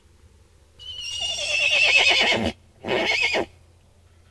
צהלת סוס.mp3